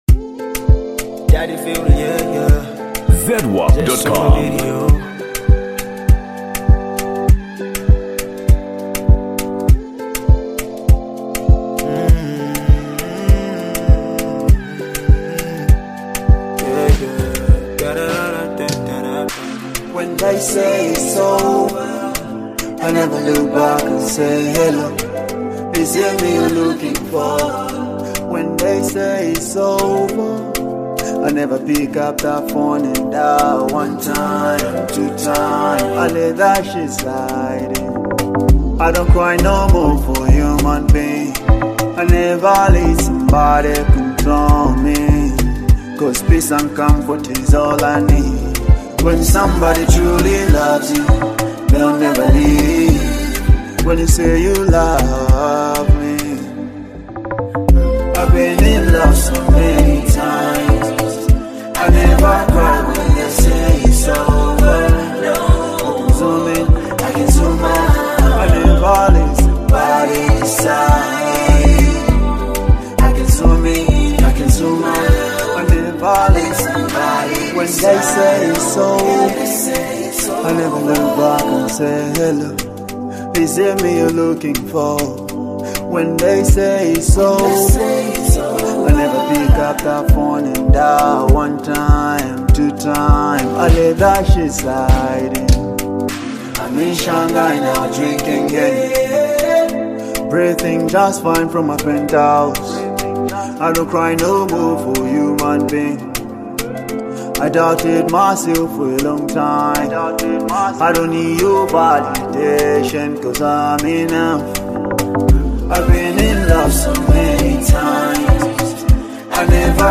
Género musical: Afro Beat